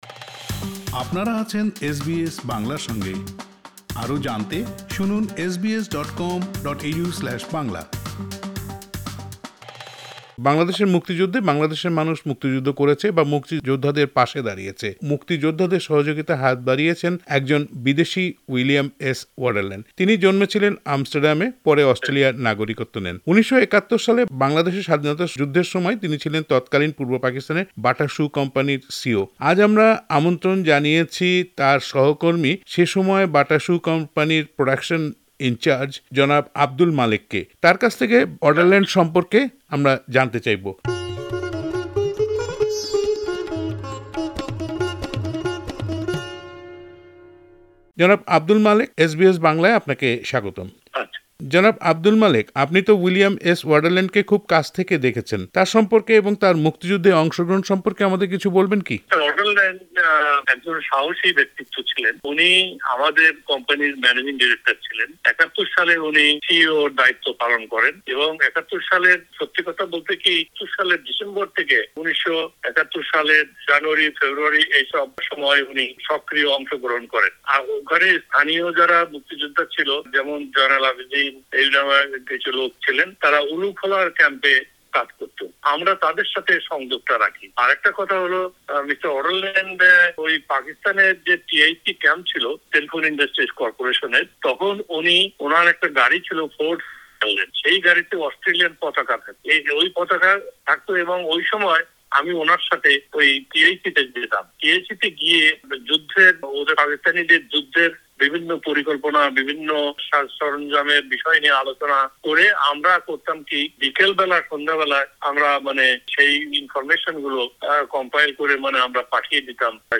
তাদের আলাপচারিতা শুনতে ওপরের অডিও প্লেয়ারটিতে ক্লিক করুন।